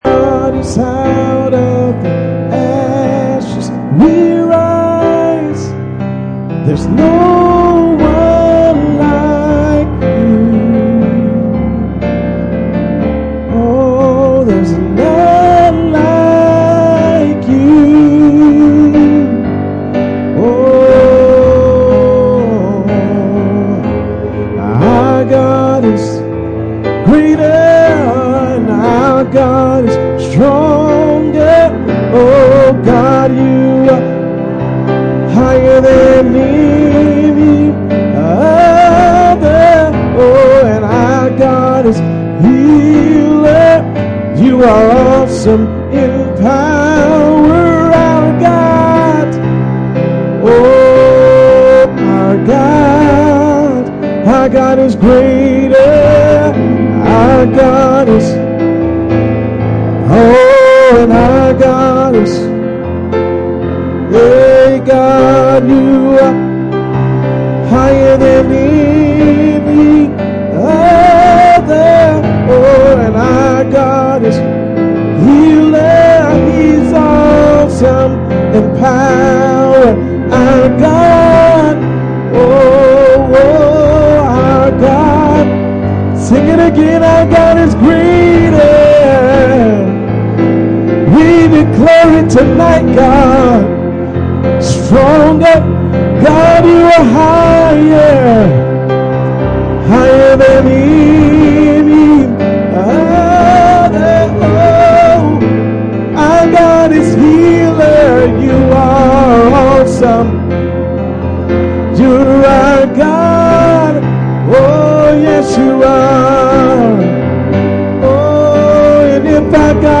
Mark 11:22-24 Service Type: Friday Night %todo_render% « Study On I Samuel 15